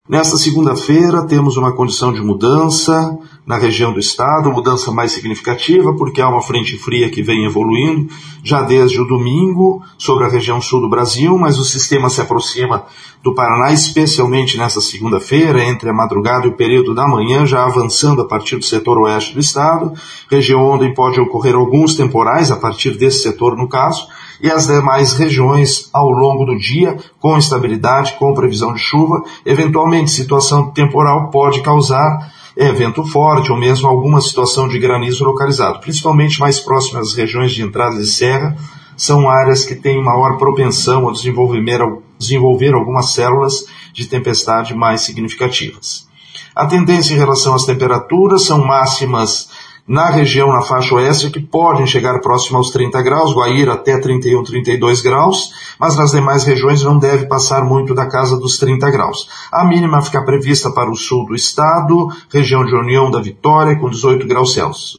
Nesta segunda, são previstas pancadas de chuva com risco de temporais, a partir das regiões oeste e sudoeste do Paraná. Ouça os detalhes com o meteorologista do Simepar